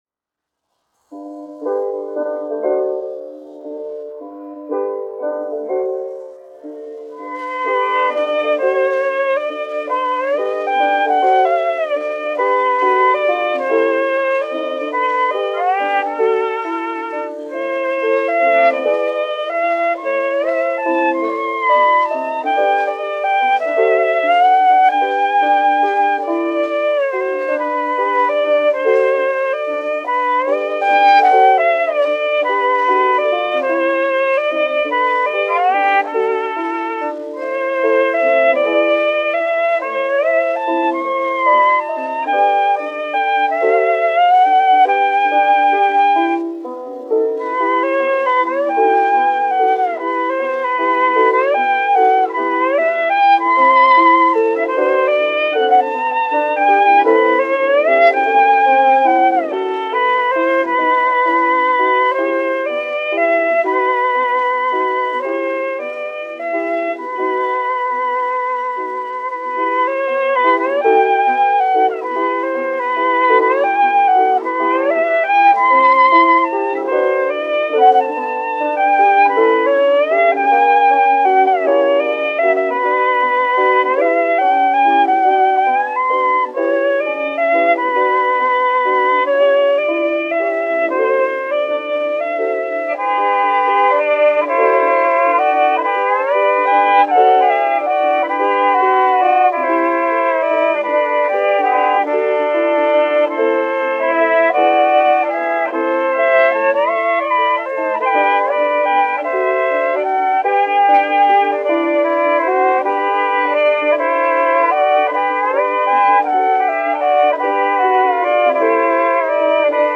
1 skpl. : analogs, 78 apgr/min, mono ; 25 cm
Vijoles un klavieru mūzika
Latvijas vēsturiskie šellaka skaņuplašu ieraksti (Kolekcija)